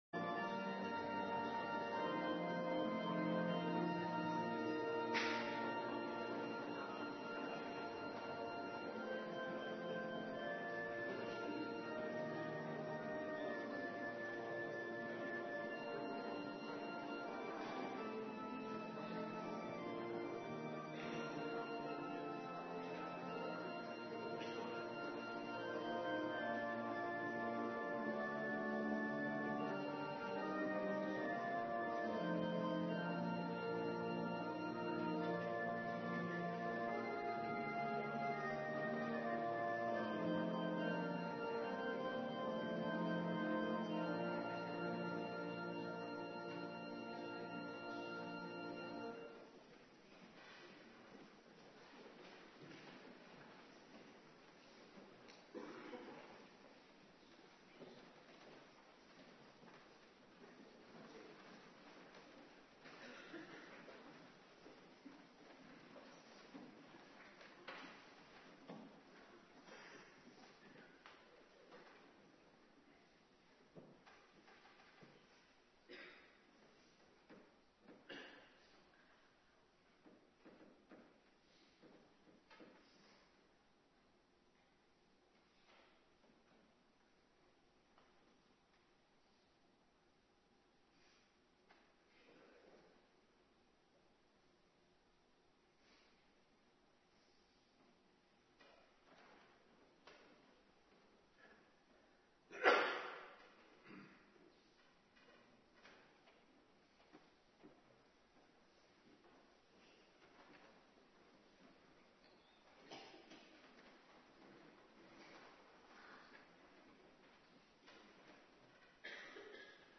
Preken (tekstversie) - Geschriften - De tweede oorzaak van het huwelijk | Hervormd Waarder